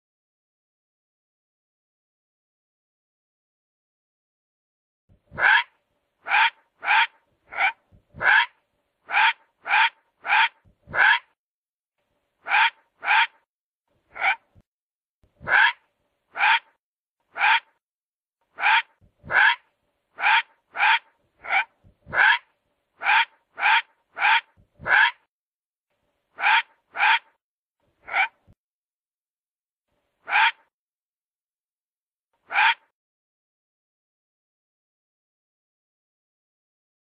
Sonido del Zorro.sonidos cortos de animales.mp3